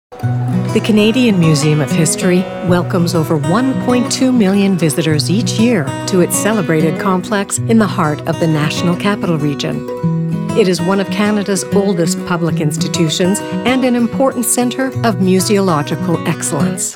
Female
Adult (30-50), Older Sound (50+)
With decades of experience, my sound is authentic, welcoming, guiding, real, nurturing, believable, and warm.
Documentary
Welcoming, Guiding